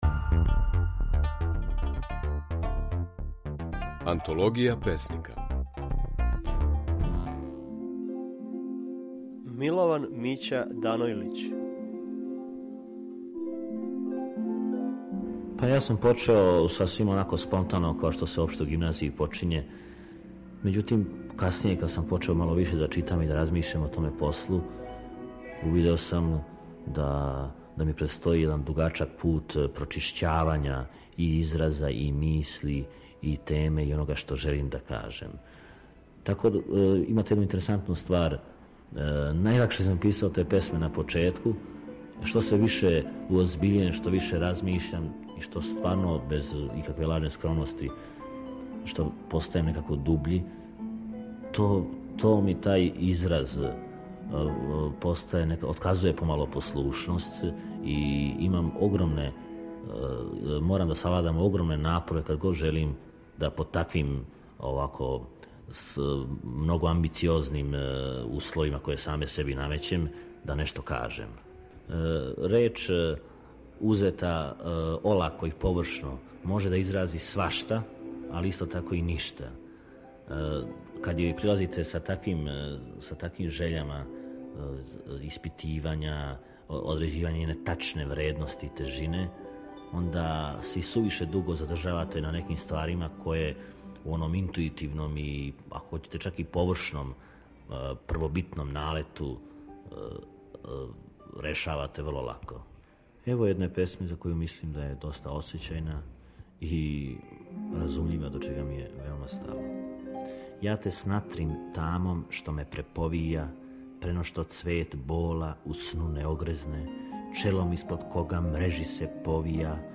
Можете чути како своје стихове говори Милован Мића Данојлић.